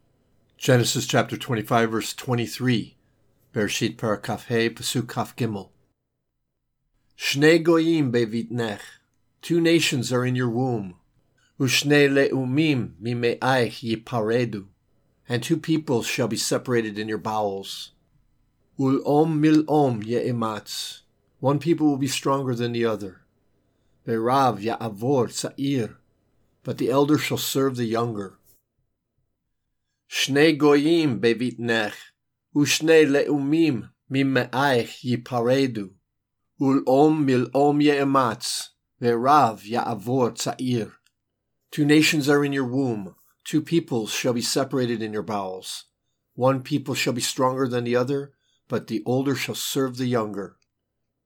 Genesis 25:23reading (click):